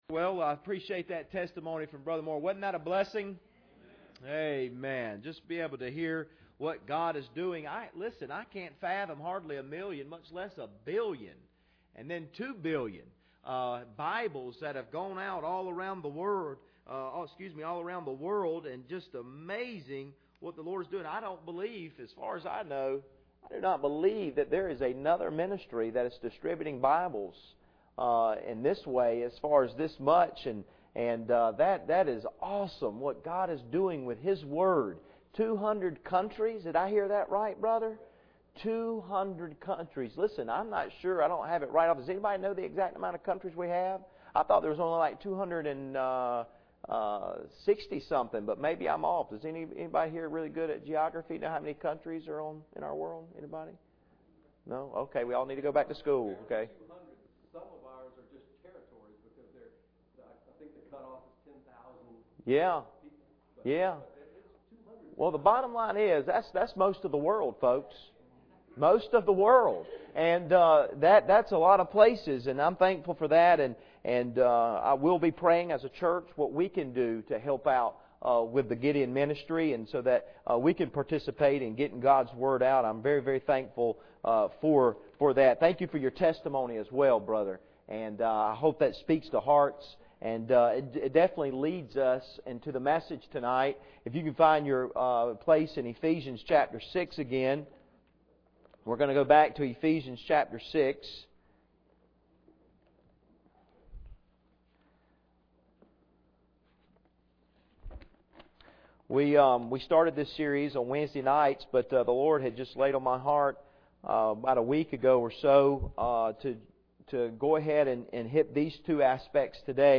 Passage: Ephesians 6:16-17 Service Type: Sunday Evening